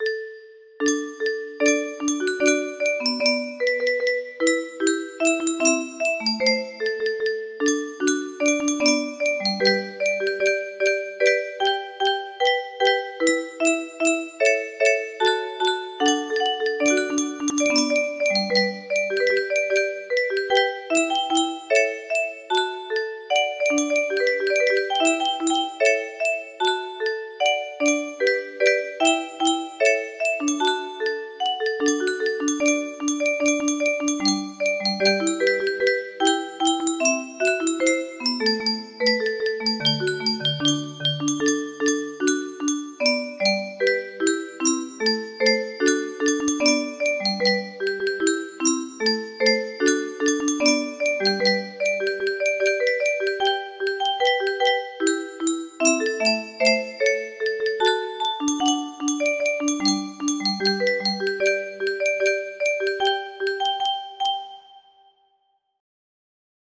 des PREZ, Josquin - Vive le Roy! music box melody
Yay! It looks like this melody can be played offline on a 30 note paper strip music box!